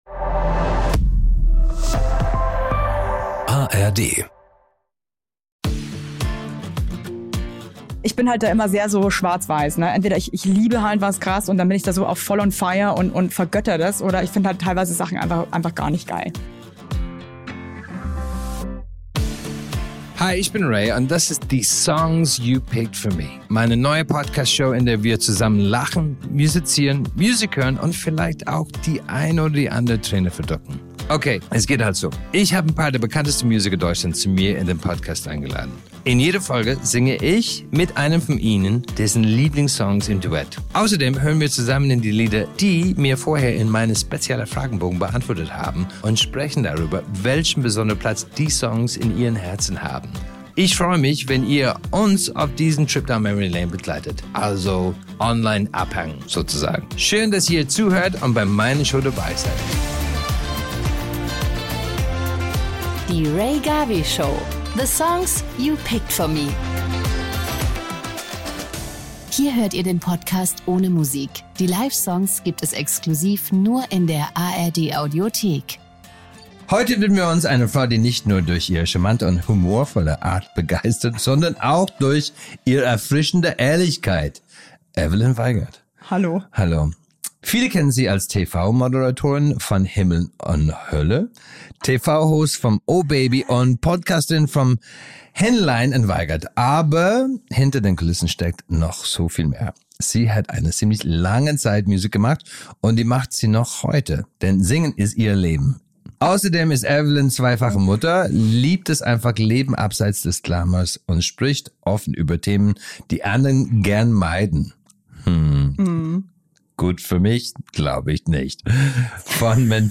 In dieser Folge begrüßt Rea Garvey Entertainerin und Sängerin Evelyn Weigert.
Mit Rea singt Evelyn zwei exklusive Coversongs, die es nur in der ARD Audiothek zu hören gibt.